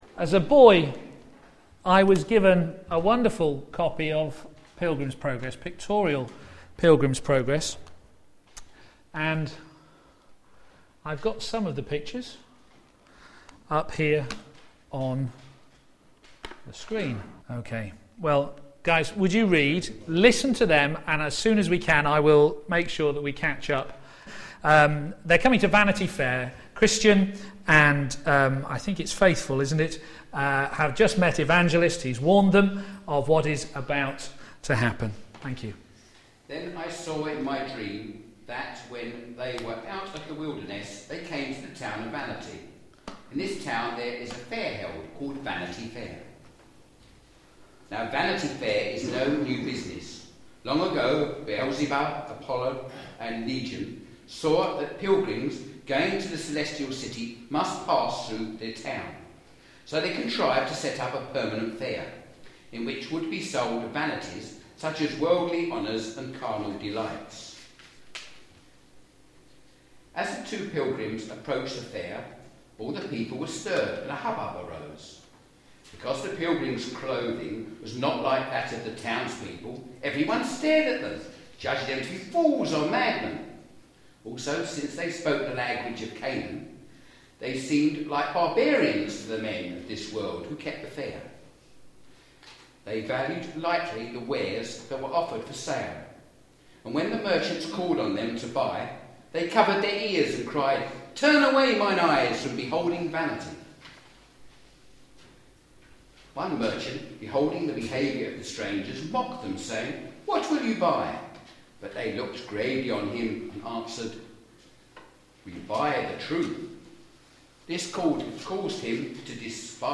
p.m. Service
Could Do Better Sermon